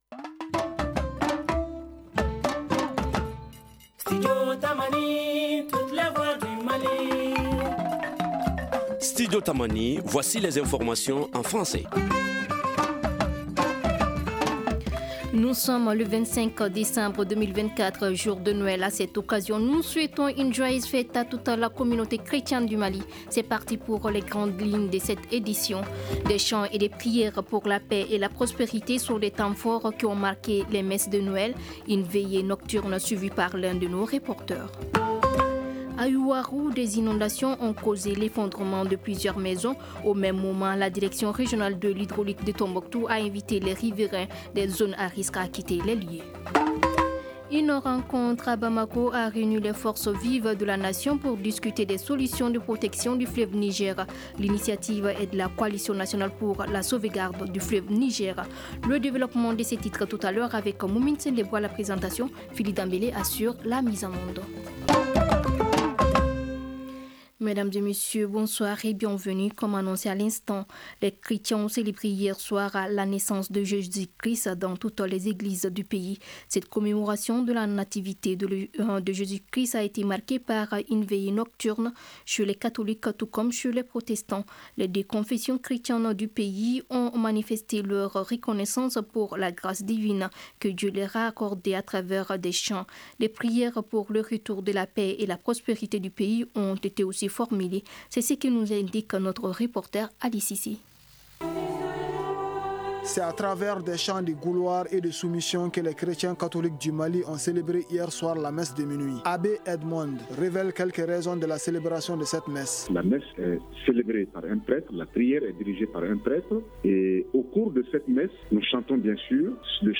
Ci-dessous, écoutez le développement de ces titres dans le journal en français :